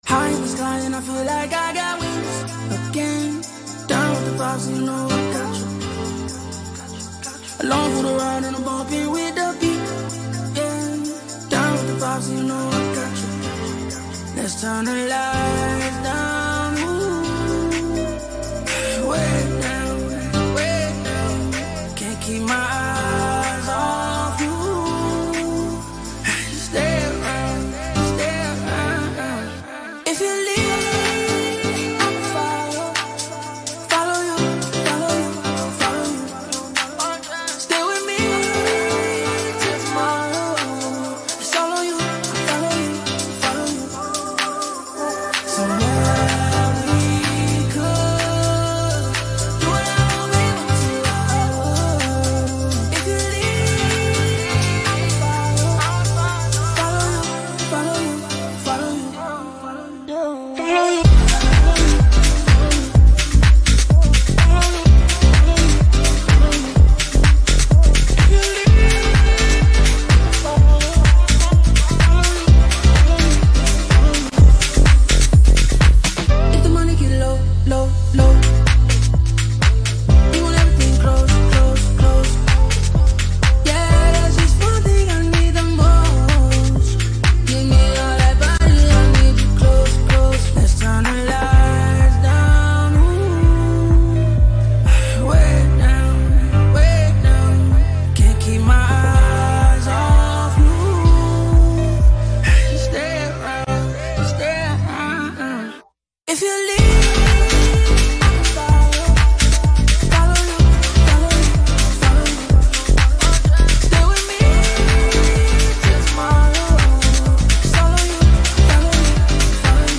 Liveset
Genre: Electronica